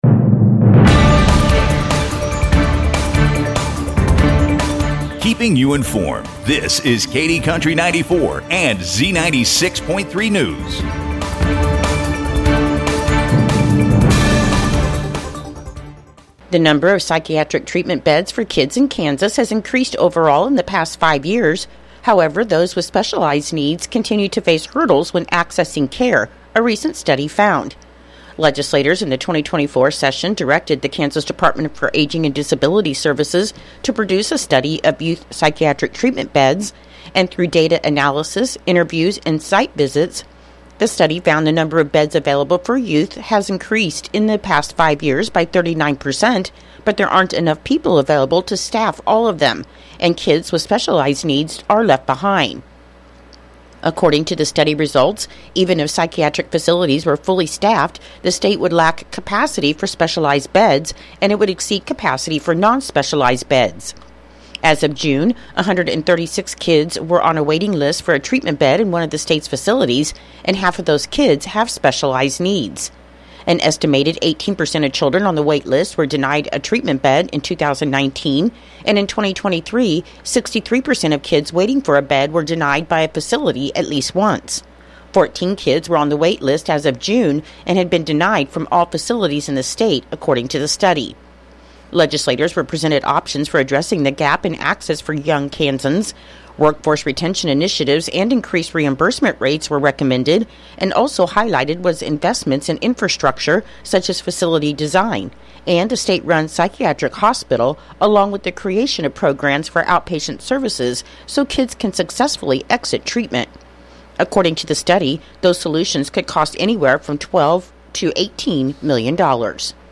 KDNS NEWS PODCAST